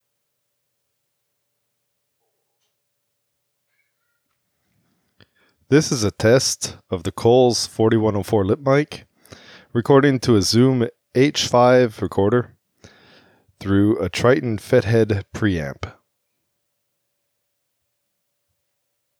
It seems to be missing electrical noises. I got it to pass AudioBook Technical Standards, but it still sounds like you’re trying to present from an airplane. Breath noises and P popping.
The kids in the background are still there and I can’t get rid of them with Noise Reduction. Because of the way noise sampling works, the best I can do is make them sound like Donald Duck, but quieter.
This clip has all the tools: SetRMS, Limiter, Stiff Noise Reduction and LF-Rolloff for Speech.